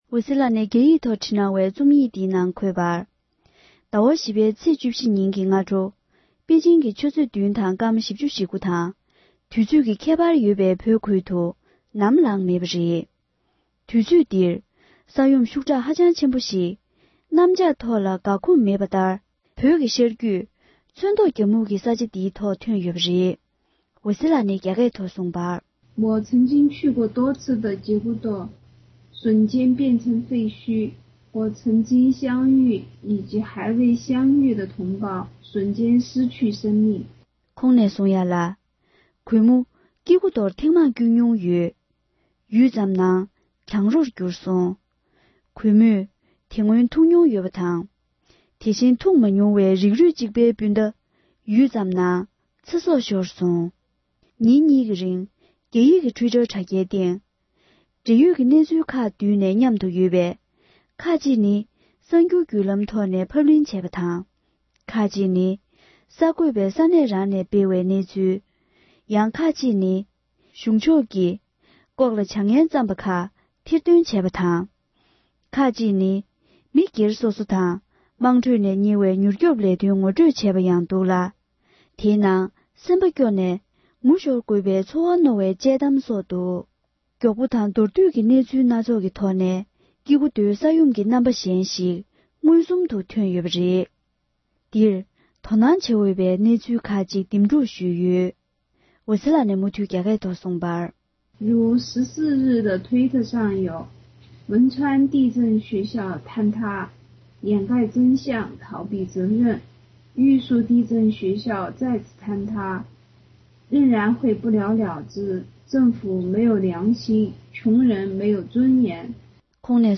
བོད་སྐད་ཐོག་ཕབ་བསྒྱུར་གྱིས་སྙན་སྒྲོན་ཞུས་པར་གསན་རོགས༎